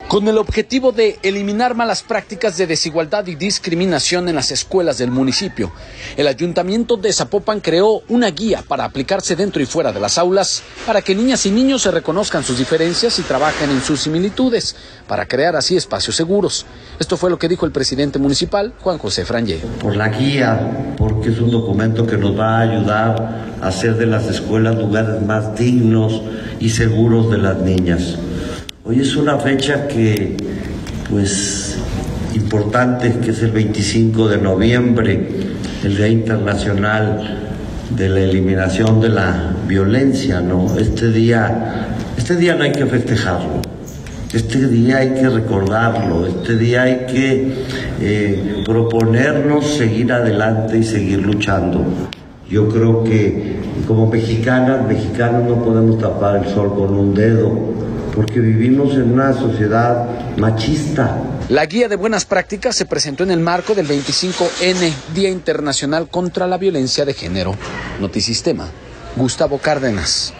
Con el objetivo de eliminar malas prácticas de desigualdad y discriminación en las escuelas del municipio, el Ayuntamiento de Zapopan creó una guía para aplicarse dentro y fuera de las aulas, para que niñas y niños reconozcan sus diferencias y trabajen en sus similitudes para crear así espacios seguros, esto fue lo que dijo el presidente municipal Juan José Frangie.